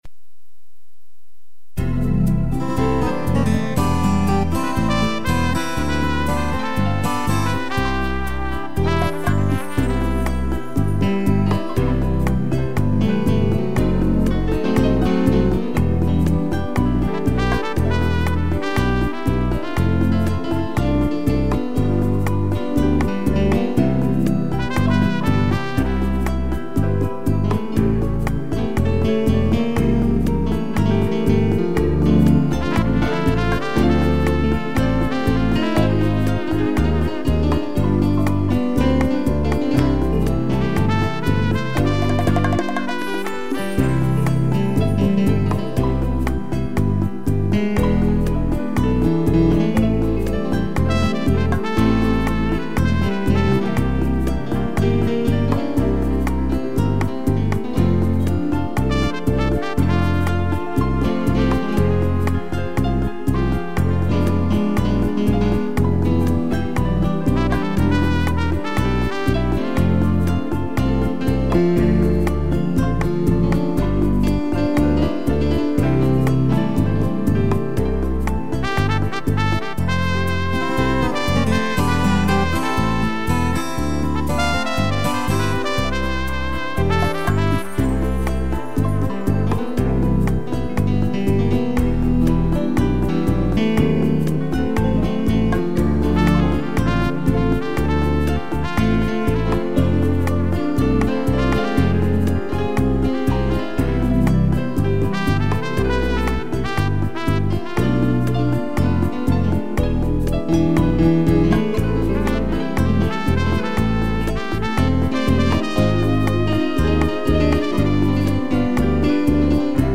piano e trompete
(instrumental)